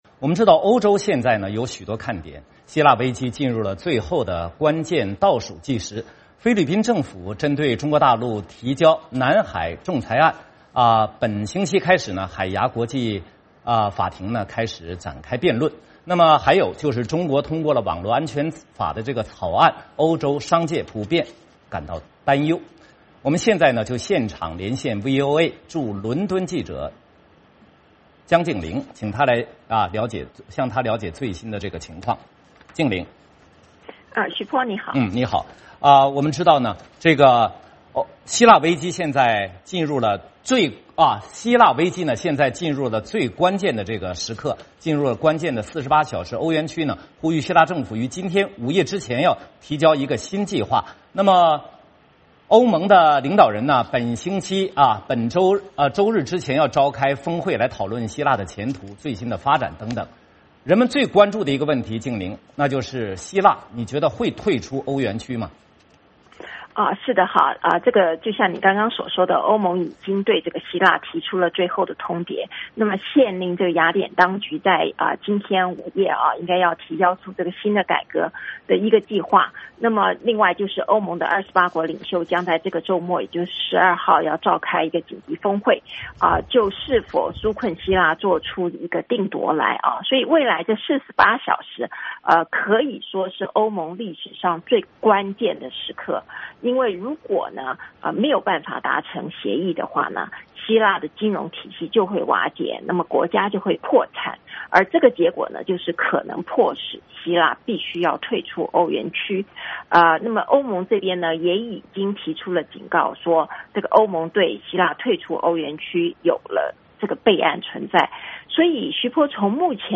VOA连线：希腊债务危机进入关键倒计时 欧洲商界关注中国网络安全法草案